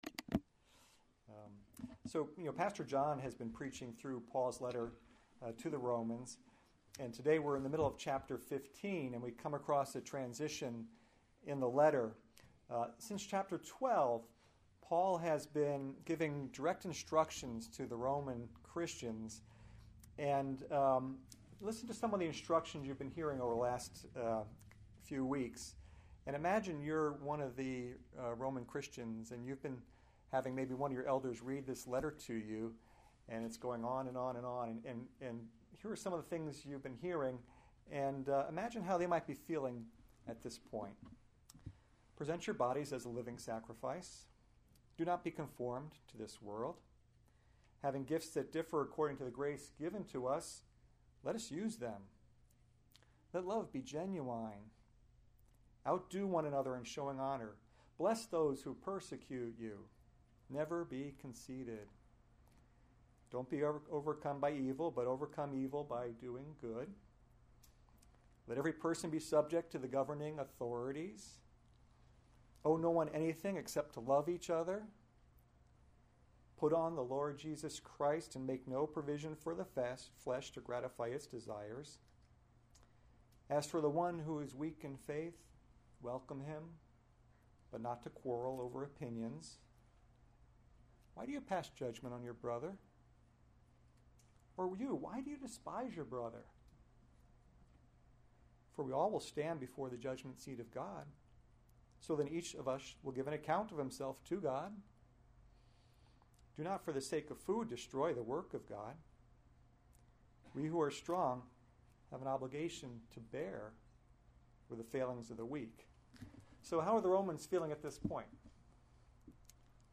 May 9, 2015 Romans – God’s Glory in Salvation series Weekly Sunday Service Save/Download this sermon Romans 15:14-21 Other sermons from Romans Paul the Minister to the Gentiles 14 I myself […]